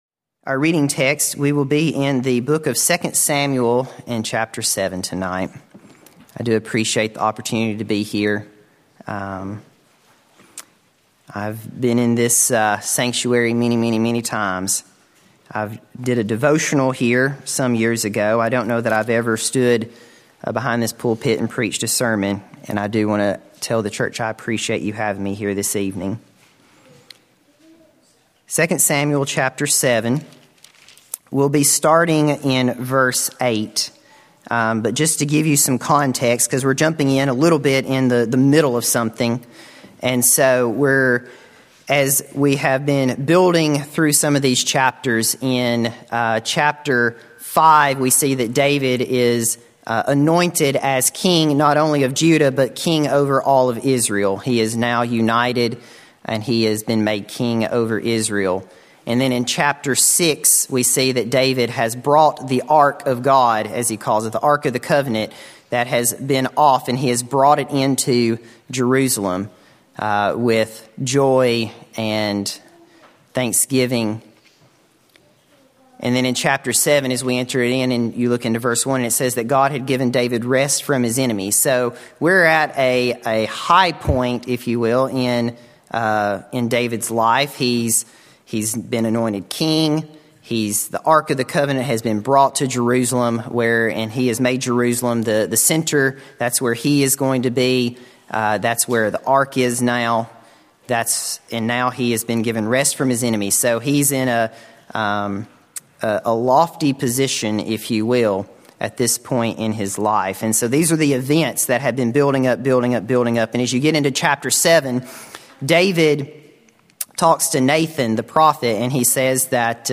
From Series: "Sunday Evening Sermons"